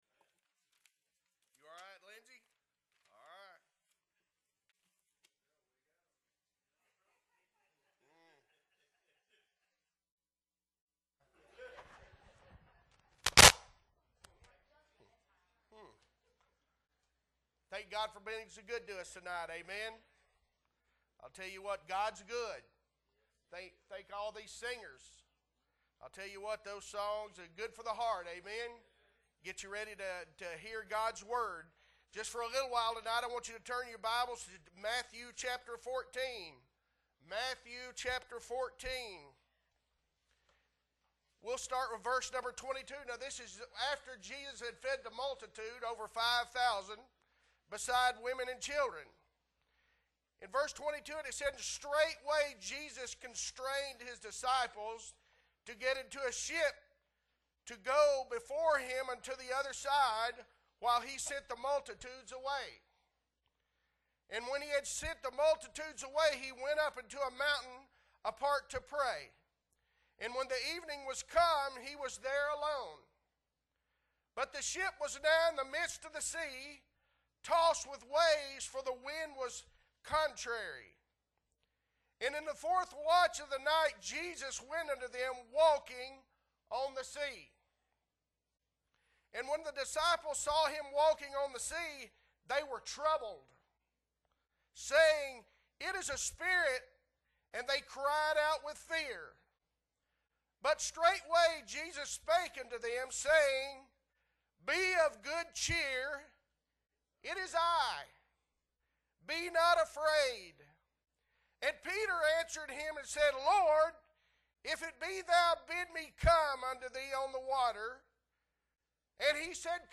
October 16, 2022 Evening Service - Appleby Baptist Church